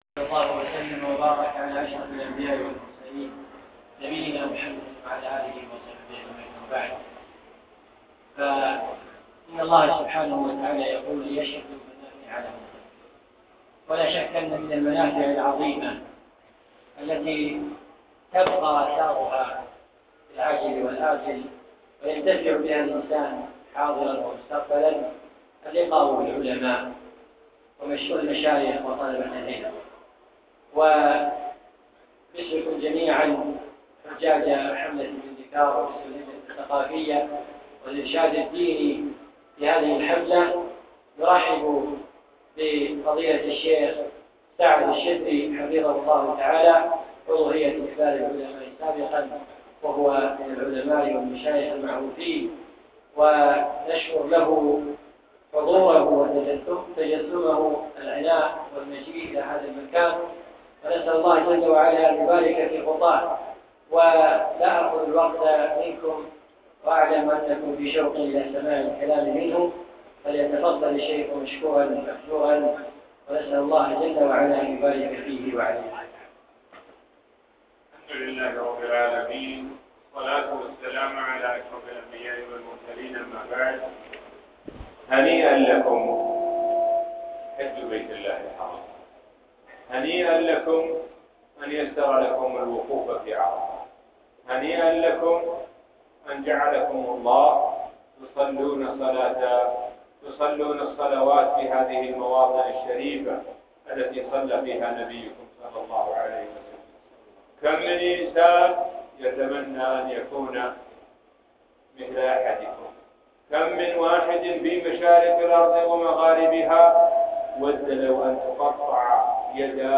ألقيت الكلمة في حملة مندكار